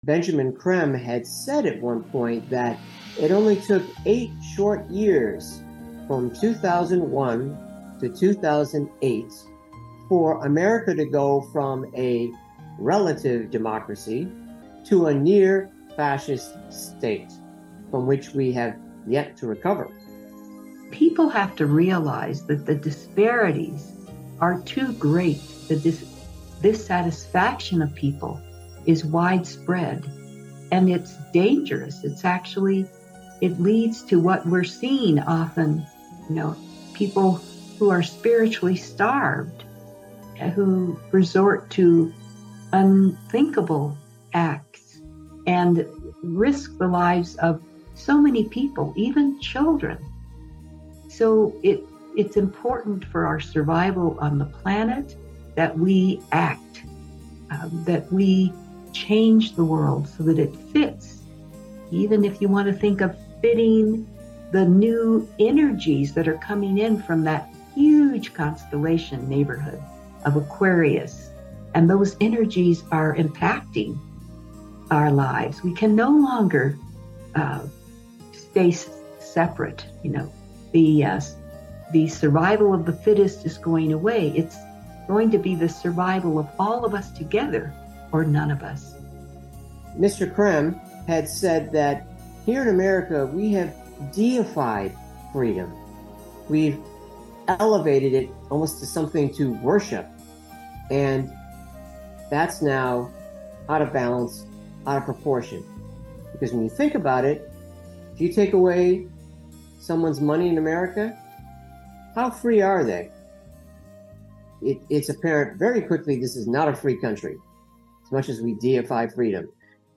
Talk Show Episode, Audio Podcast, Planetary MakeOver Show and It's Our Choice to Keep World Fascism or Build a World Family – Act Now!